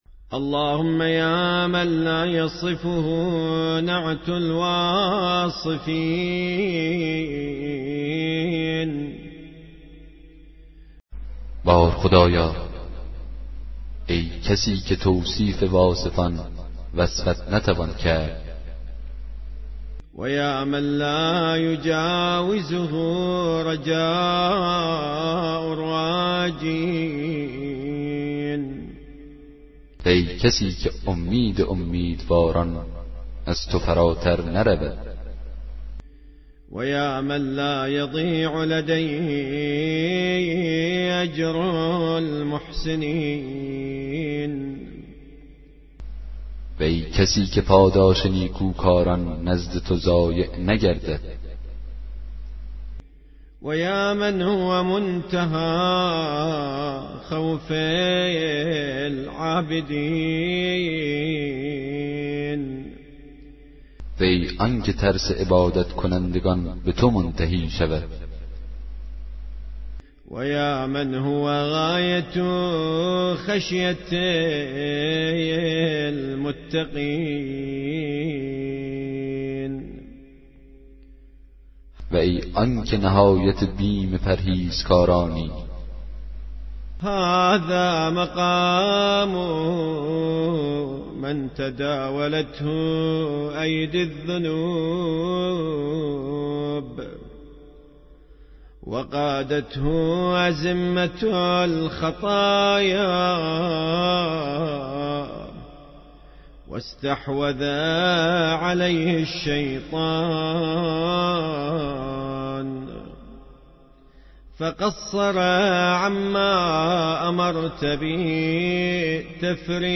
دسته بندی : کتاب صوتی